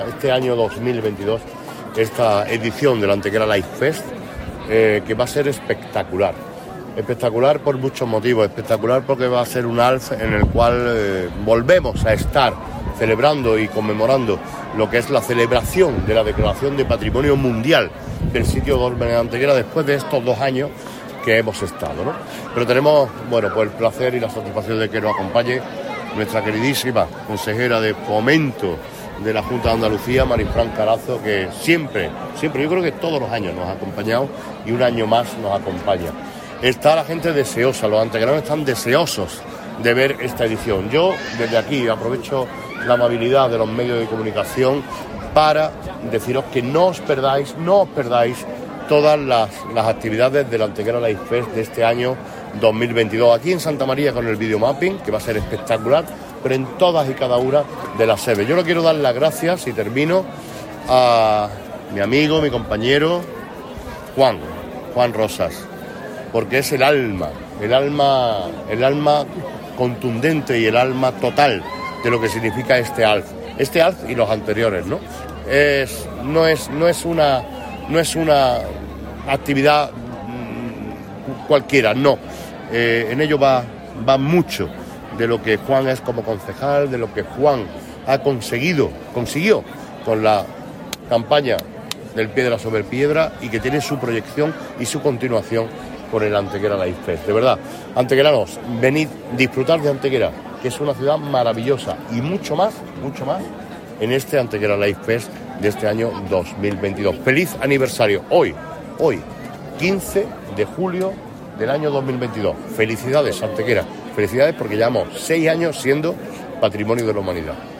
Momentos antes, el alcalde Manolo Barón -que estuvo acompañado por varios de sus compañeros integrantes del Equipo de Gobierno así como por la consejera de Fomento de la Junta de Andalucía, Marifrán Carazo- realizaba unas declaraciones a los medios de comunicación en las que manifestó su satisfacción por el hecho de poder conmemorar los seis años desde que Antequera es Patrimonio de la Humanidad gracias al Sitio de los Dólmenes, haciéndolo además a través de un hito especial como es el ALF.
Cortes de voz